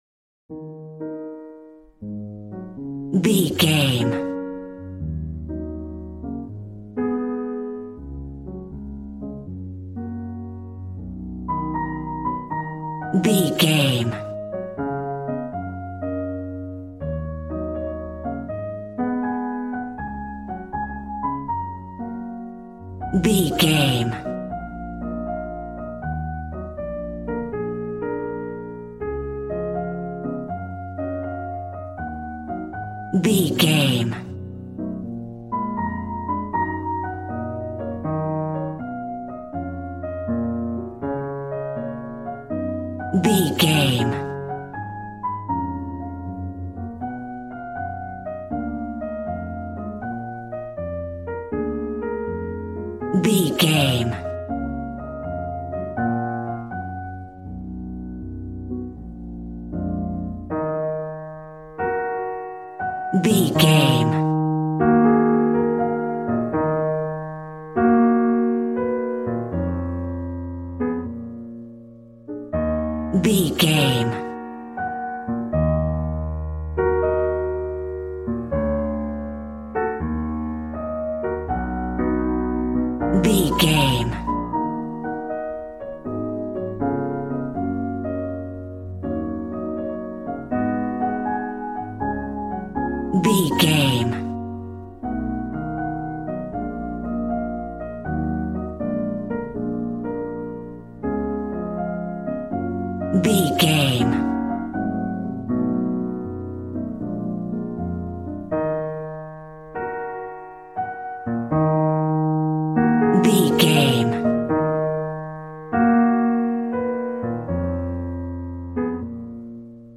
Aeolian/Minor
E♭
drums